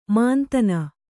♪ māntana